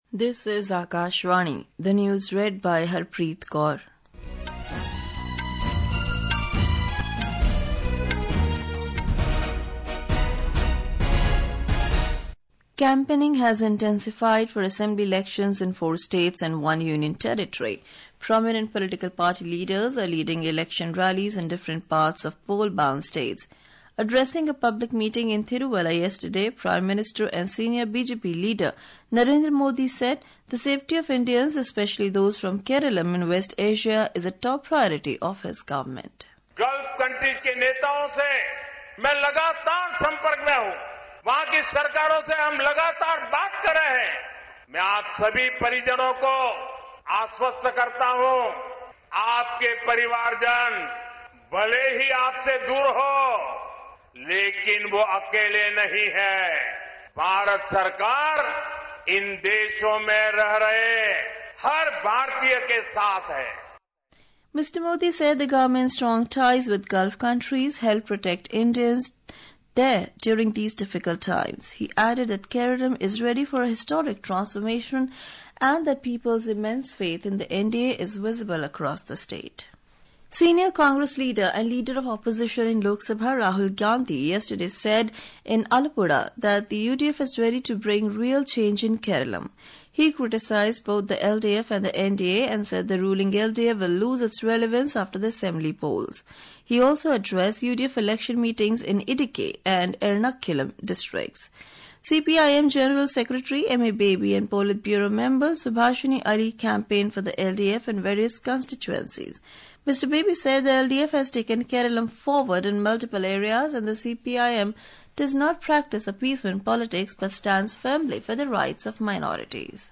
રાષ્ટ્રીય બુલેટિન
Hourly News